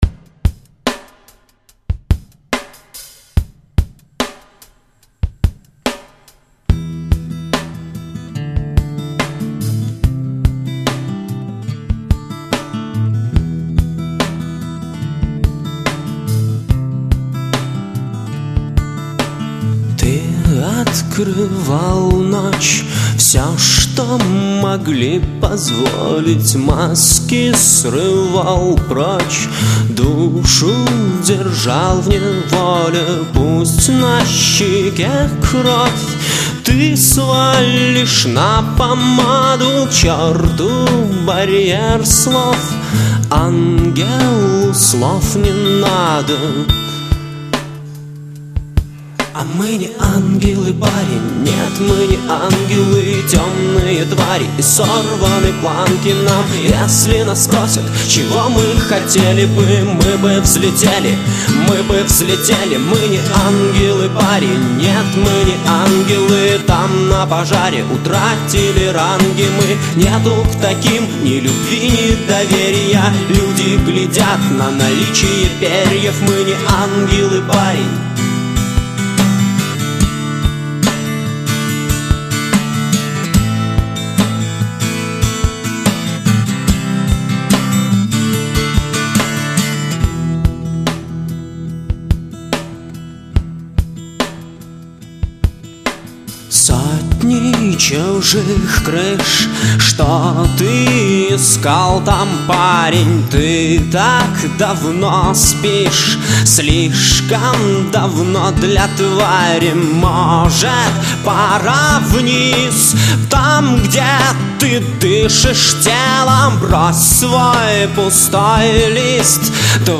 ну дык записывали просто как демку) тут даже 3го куплета нет e14
поет и играет он, текст тоже его ok